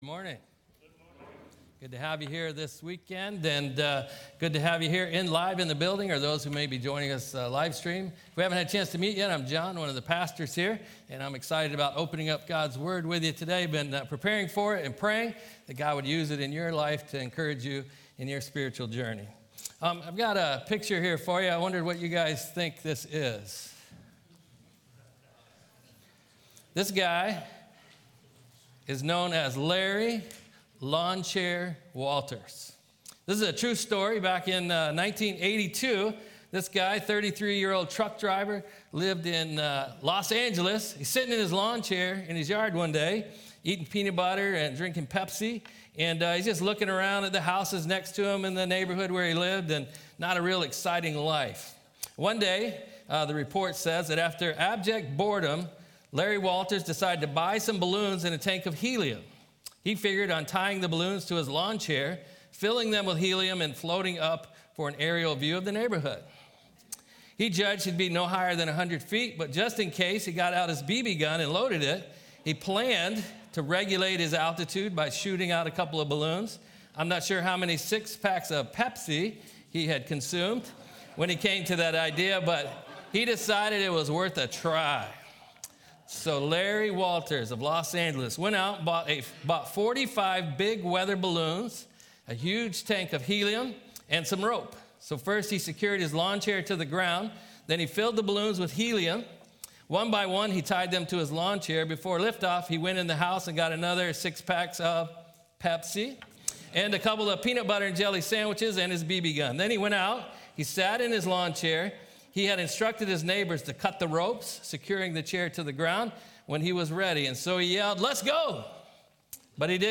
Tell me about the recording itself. April-6-Service.m4a